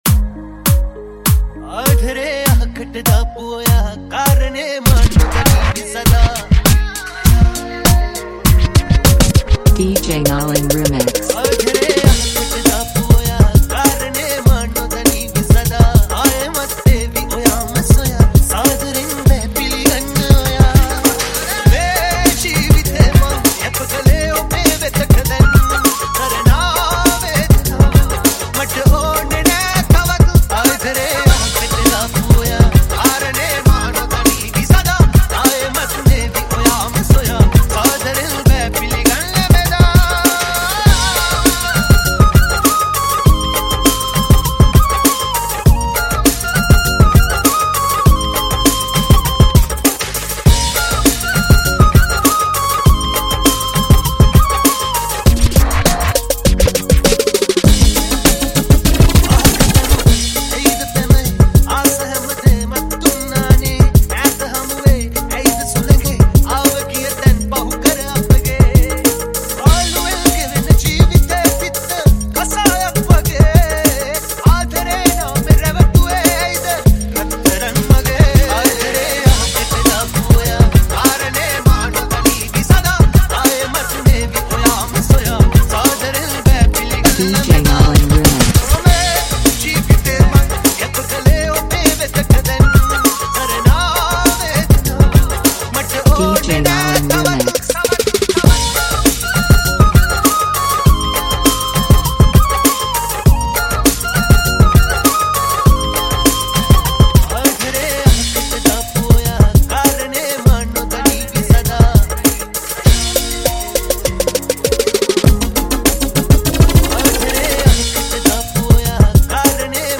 Dj Remix song